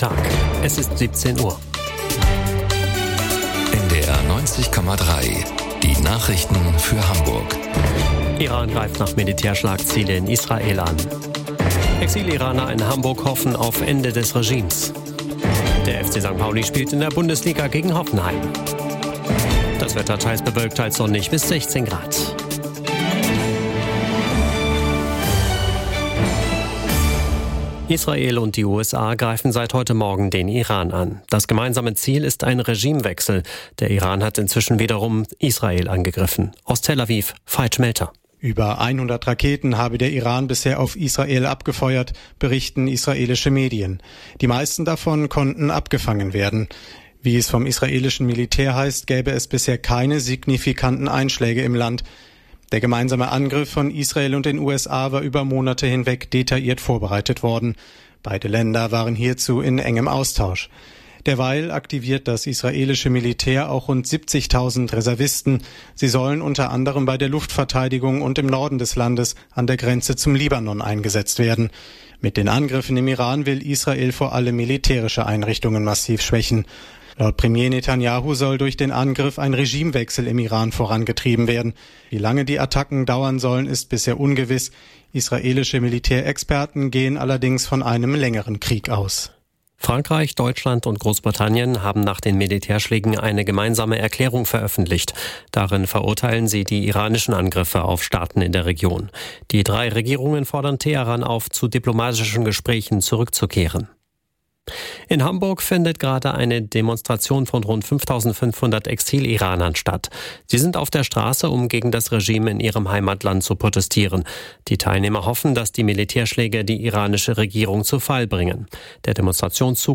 Genres: Daily News, News